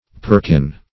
perkin - definition of perkin - synonyms, pronunciation, spelling from Free Dictionary
Perkin \Per"kin\ (p[~e]r"k[i^]n), n.